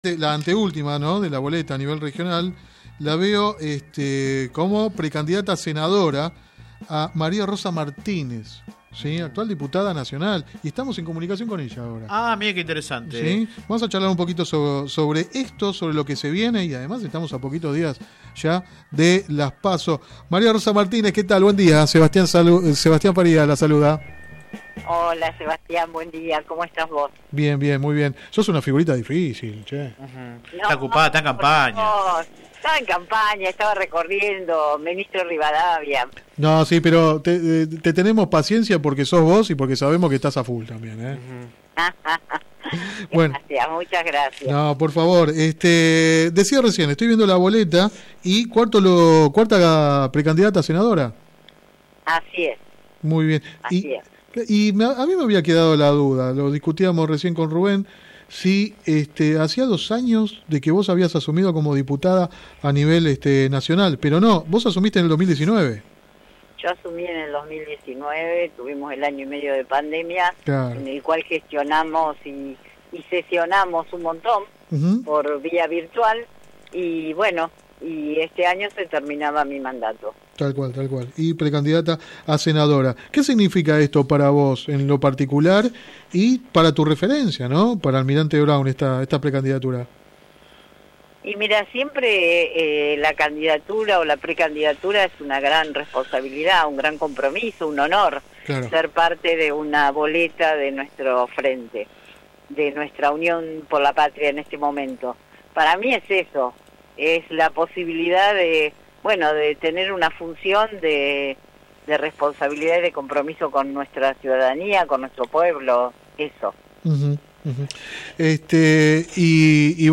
entrevista radial